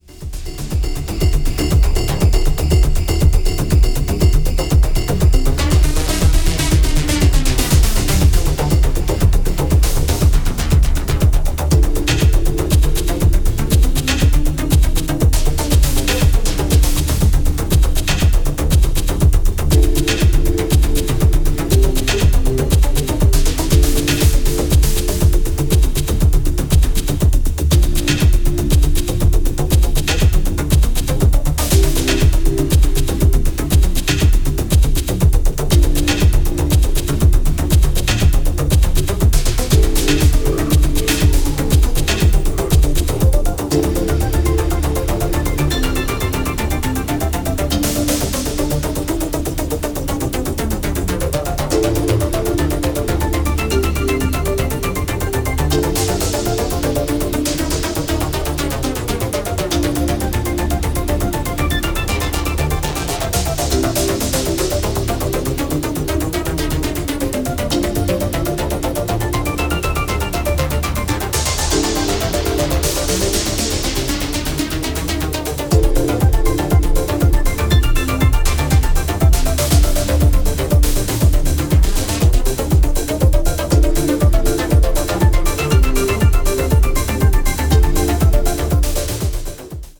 hypnotic house and tropical flare
drum machines, synths